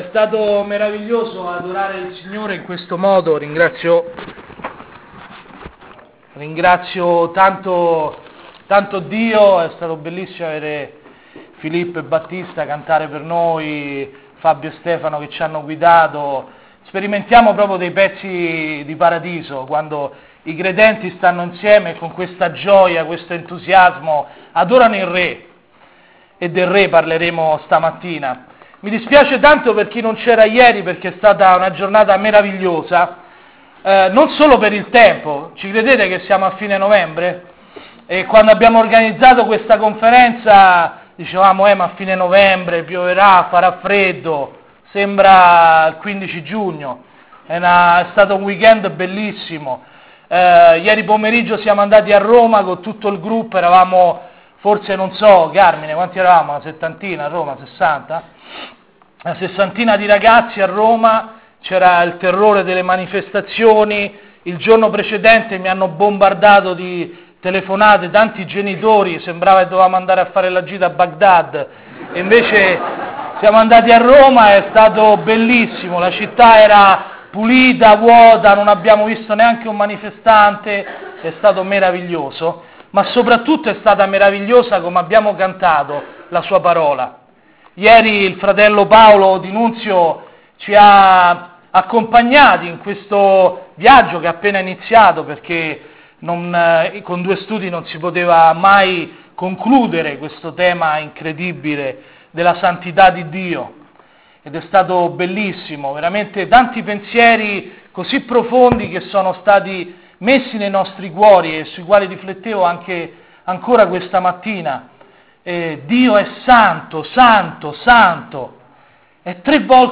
Nella seconda giornata del convegno giovani a Dragona, meditazioni sul passo di Luca 19.